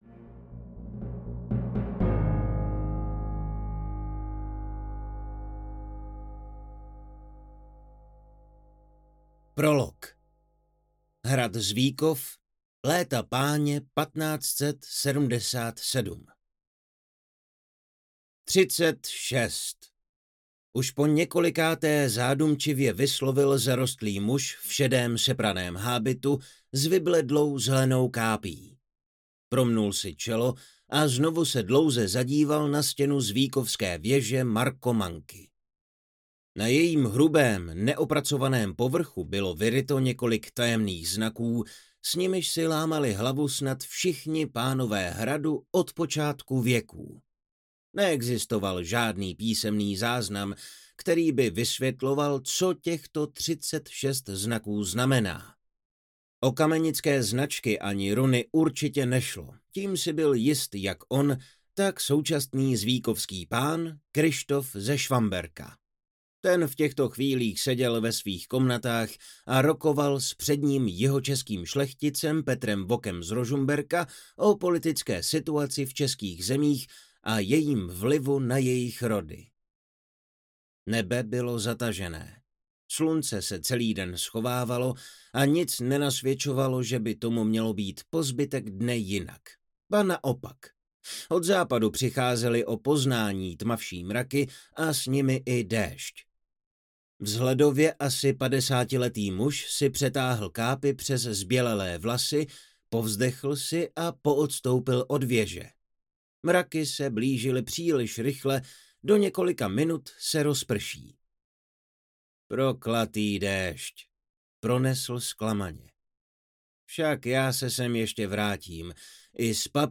Krev padlých andělů audiokniha
Ukázka z knihy